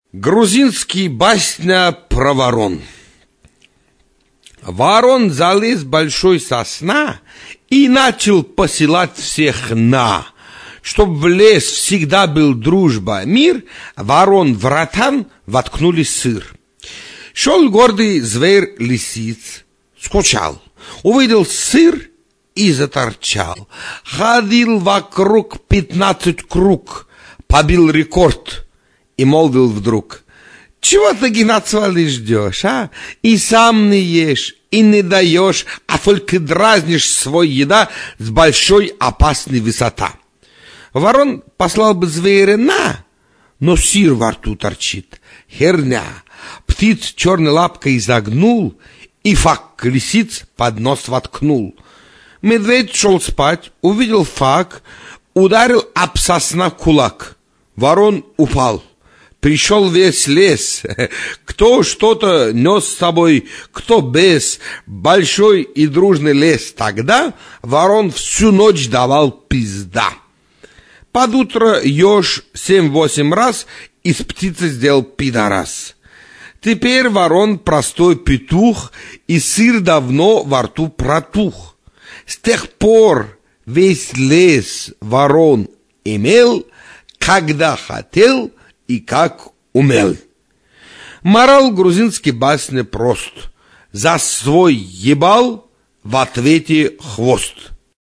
Грузинский басня про ворон! (С ненормативной лексикой!)